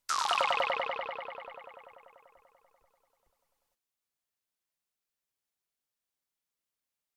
狐狸交配的叫声
描述：这是一个快速录制的狐狸或狐狸精交配的叫声。它是通过将我的录音机放在卧室的窗外而迅速完成的。这种叫声不是很有规律。
声道立体声